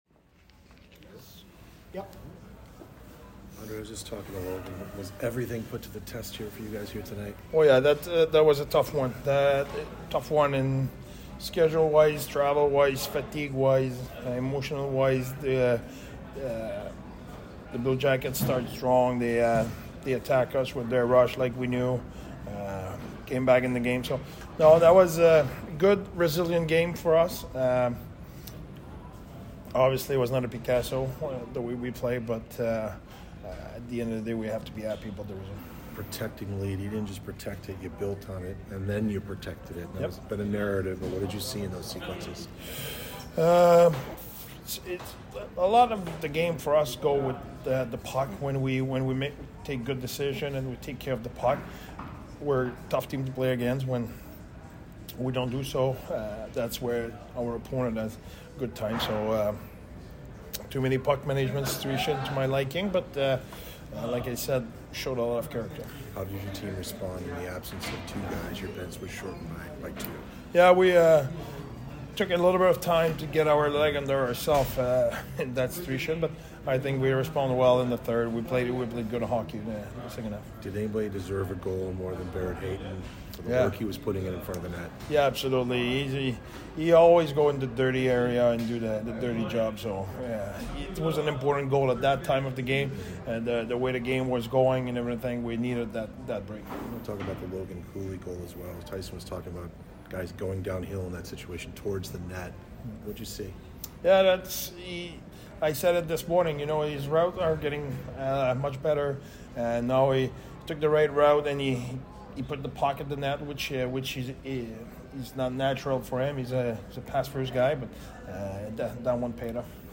COYOTES POST-GAME AUDIO INTERVIEWS
HC Andre Tourigny |